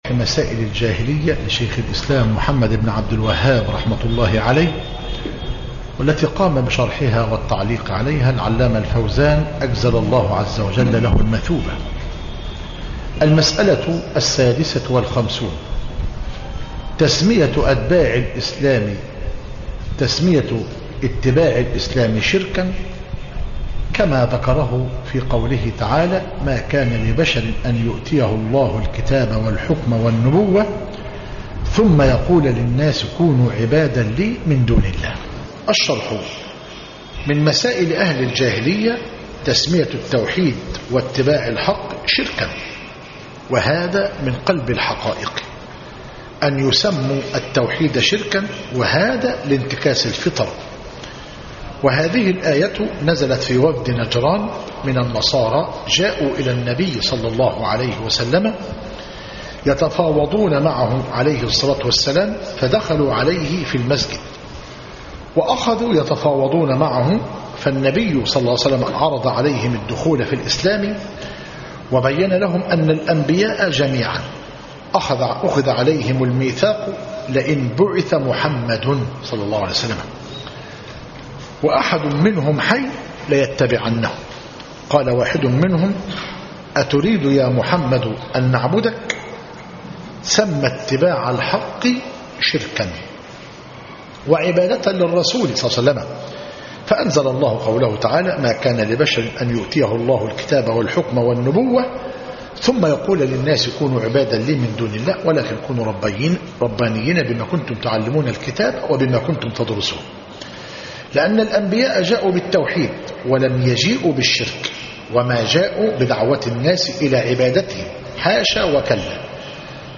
مسائل الجاهلية للعلامة الفوزان - مسجد عباد الرحمن - المنايل - كفر حمزة - قليوبية - المحاضرة الحادية والعشرون - بتاريخ 5- محرم - 1437هـ الموافق 18- أكتوبر- 2015 م